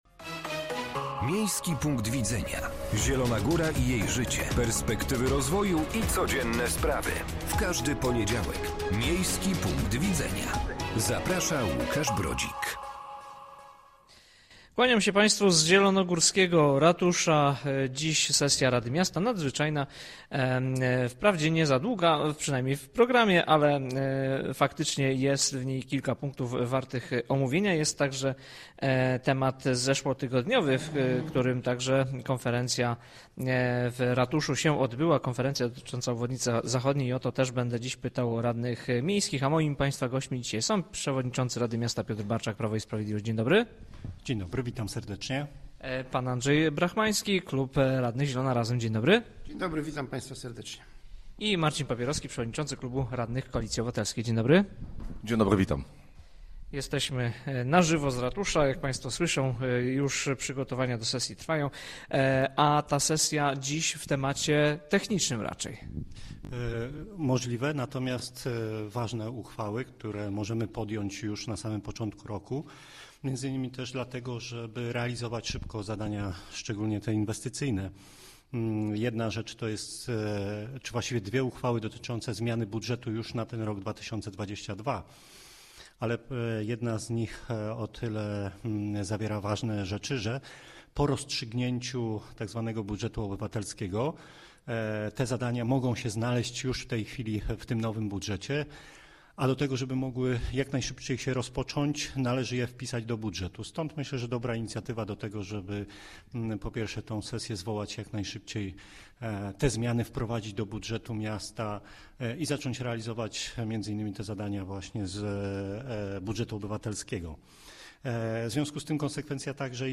Gośćmi audycji byli: Piotr Barczak - przewodniczący rady miasta, klub radnych Prawo i Sprawiedliwość, Marcin Pabierowski - przewodniczący klubu radnych